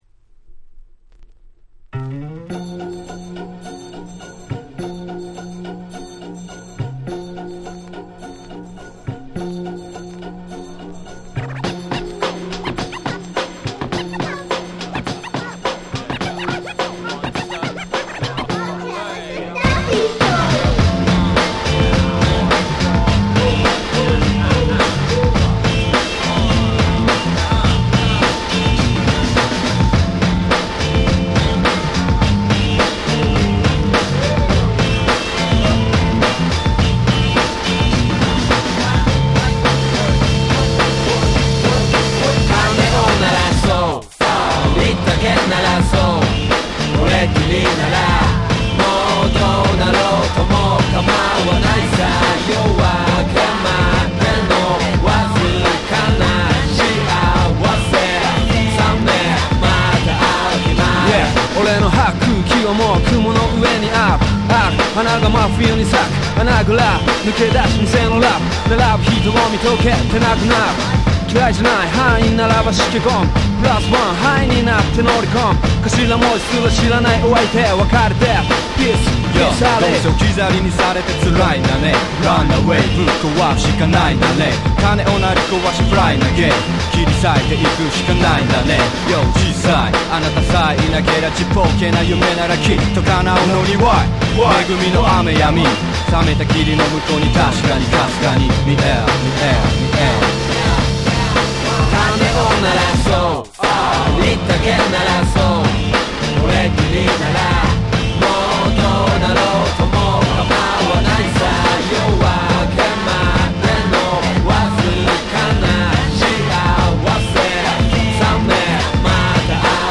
Japanese Hip Hop Classics !!